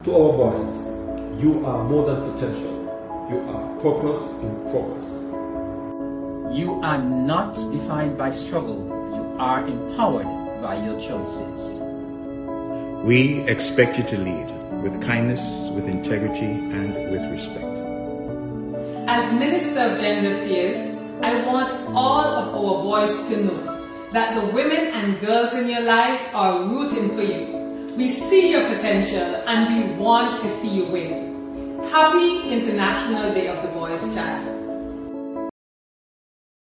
Meantime, Cabinet members of the Nevis Island Administration along with Minister of Gender Affairs, the Hon. Jahnel Nisbett shared a few words: